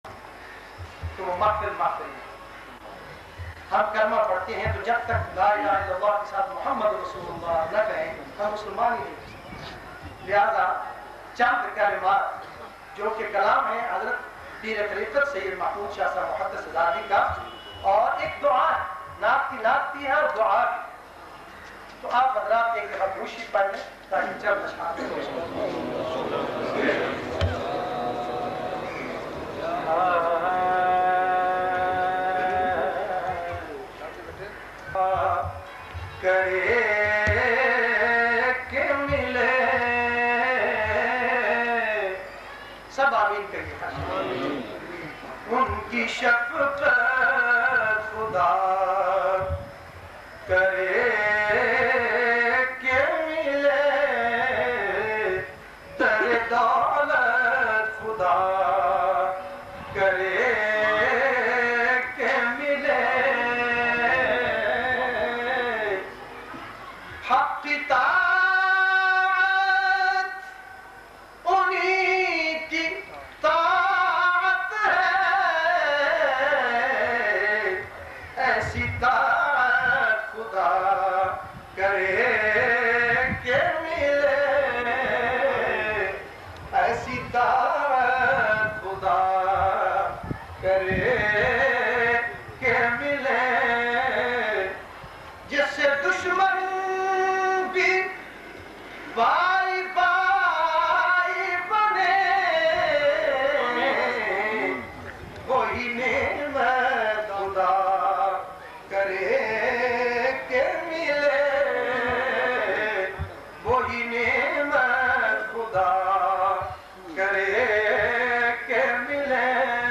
Un Ki Shafqat naat audio